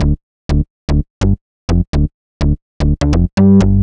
cch_bass_loop_steve_125_Dm.wav